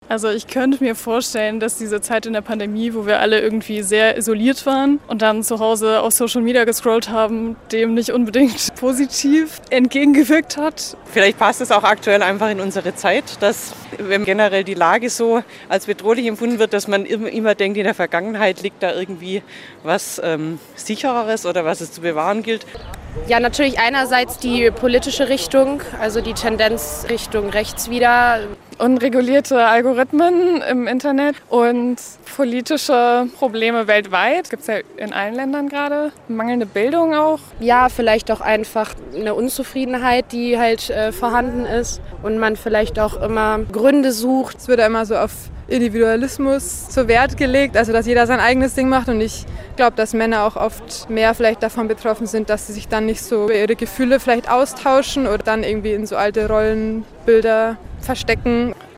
Radio- und Video-Umfrage in der Freiburger Innenstadt im Rahmen des BOGY-Praktikums im SWR Studio Freiburg.